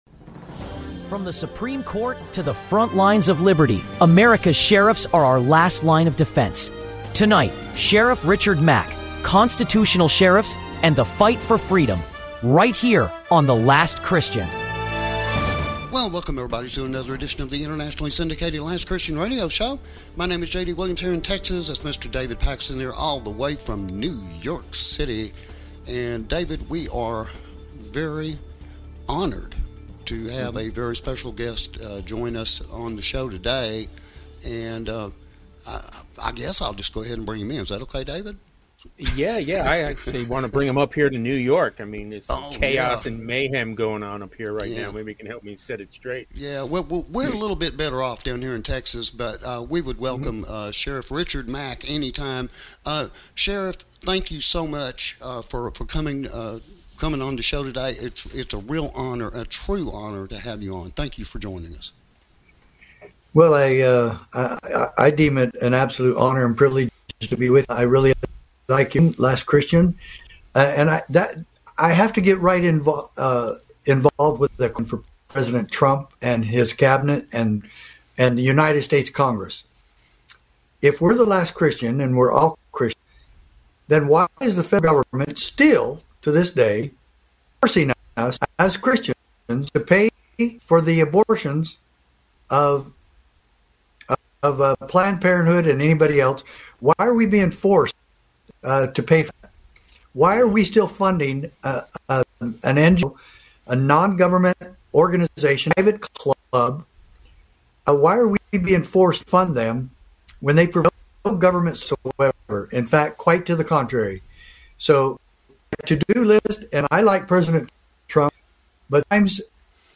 Americas Last Line Of Defense With Special Guest Sheriff Richard Mack